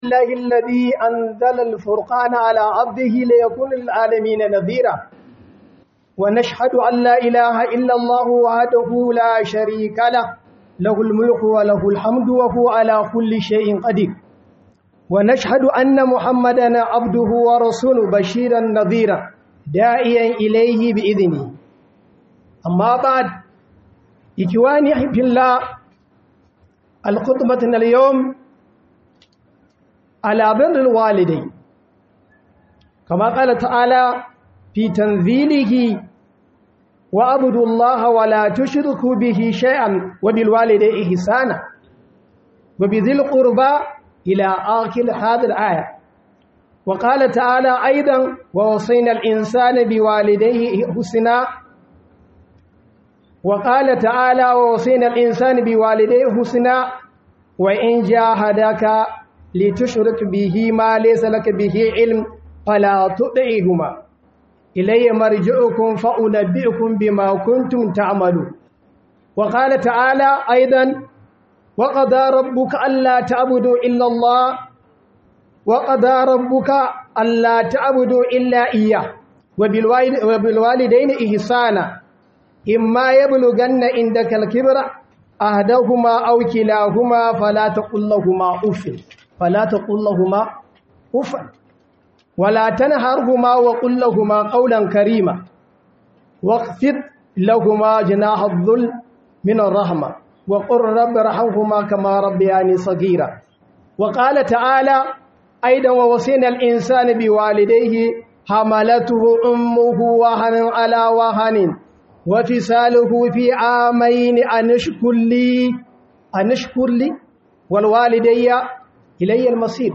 Khudubar Sallar Juma'a by JIBWIS Ningi